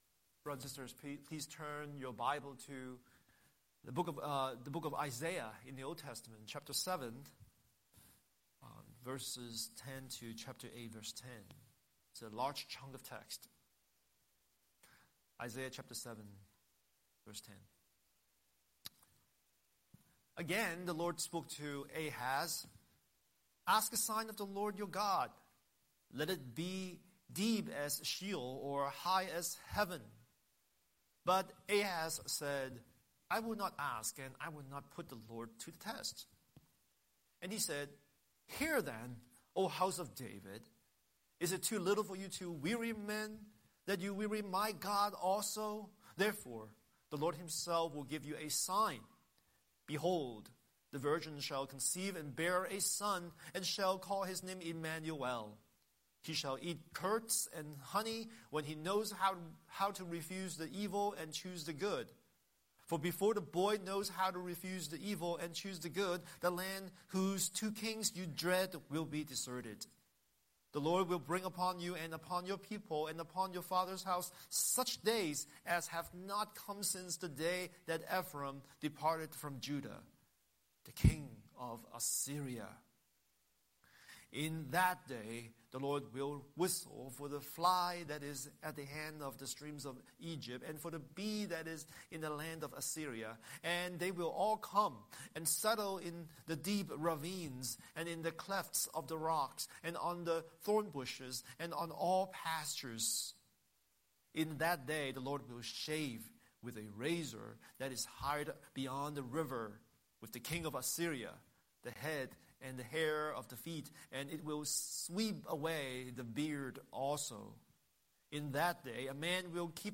Scripture: Isaiah 7:10–8:10 Series: Sunday Sermon